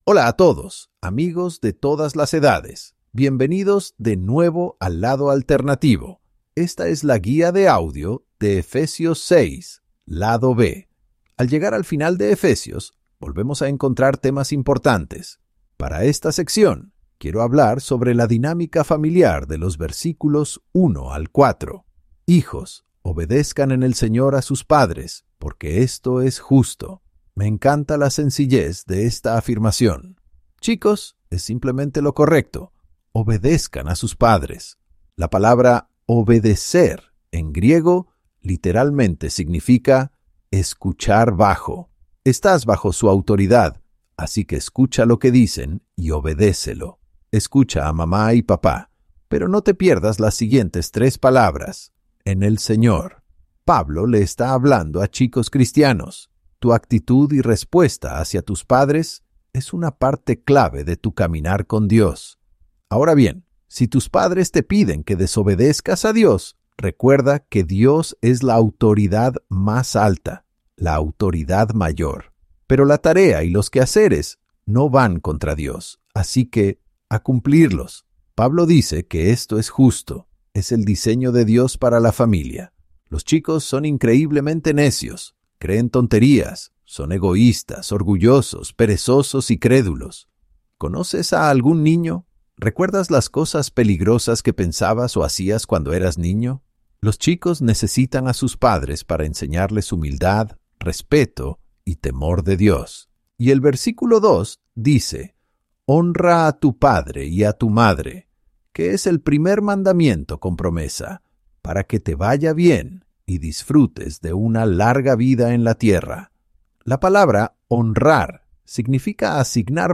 Cuando la Biblia te resulta confusa, A través de la Palabra te la explica con guías de audio claras y concisas para cada capítulo. Aquí, en Efesios, aprendemos quiénes somos y cómo vivir en base a nuestra identidad.